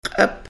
Listen to the elders